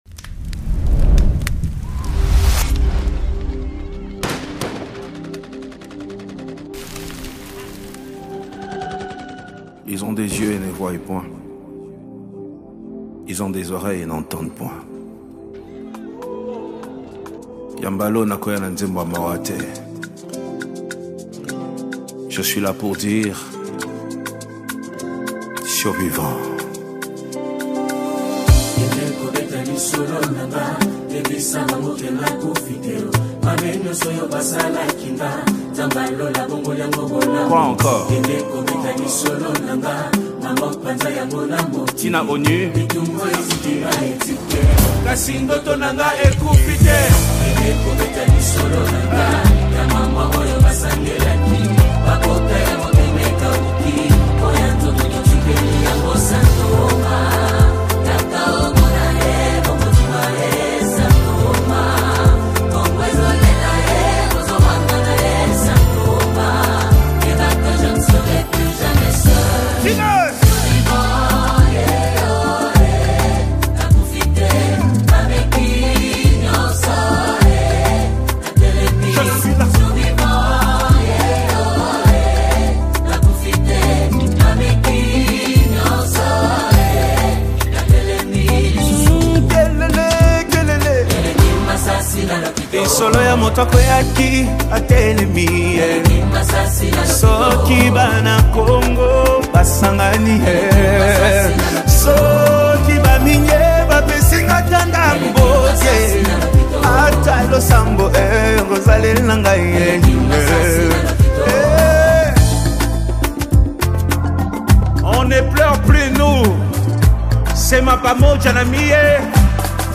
Congo Gospel Music
soul-stirring and powerful worship release
SOULFUL VOCALS, and a powerful WORSHIP ARRANGEMENT